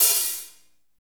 Index of /90_sSampleCDs/Northstar - Drumscapes Roland/KIT_Hip-Hop Kits/KIT_Rap Kit 3 x
HAT F S LH0G.wav